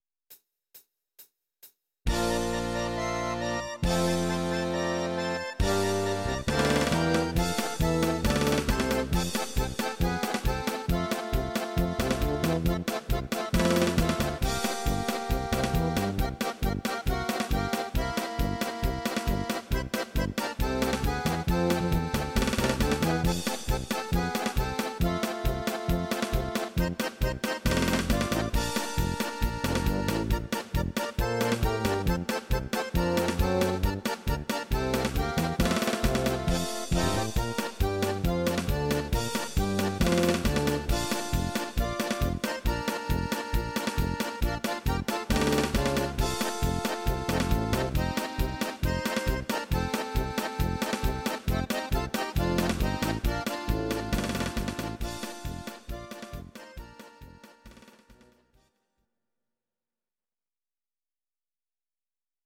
Audio Recordings based on Midi-files
Instrumental, Traditional/Folk, Volkst�mlich